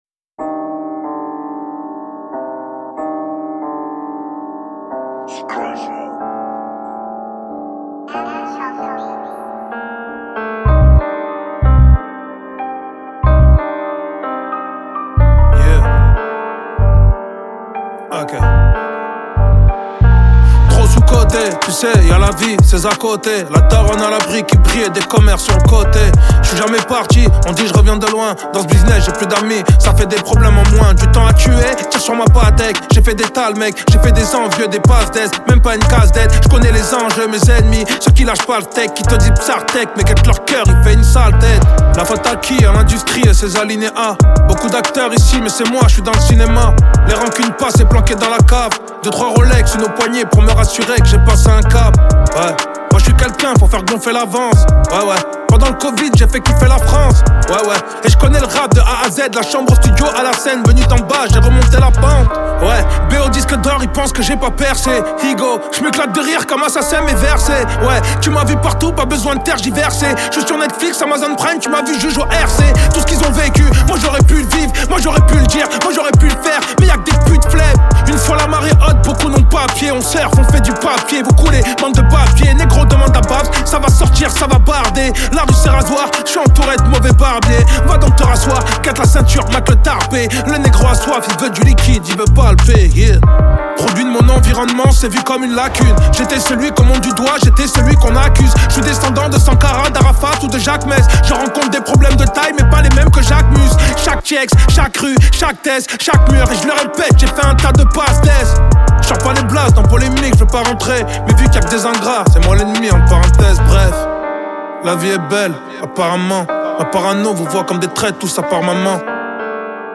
0/100 Genres : french rap Télécharger